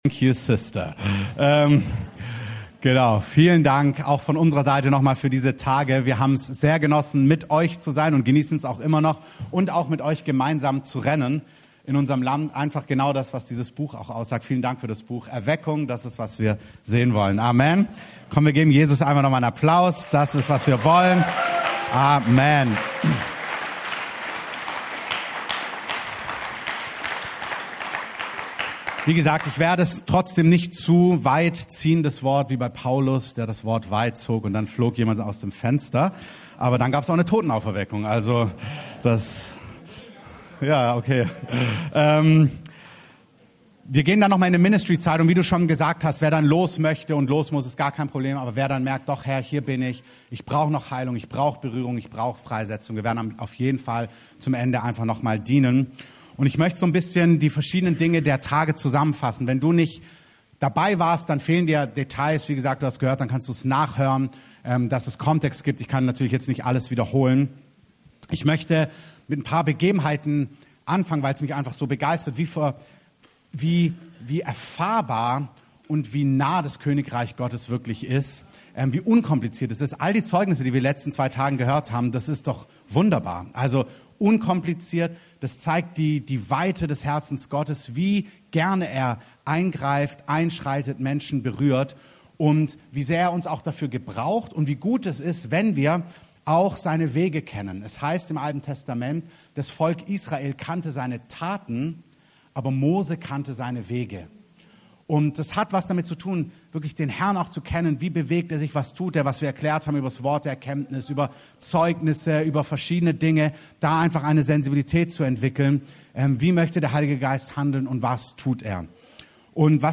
Abschluss-Predigt der WINDSTÄRKE-Konferenz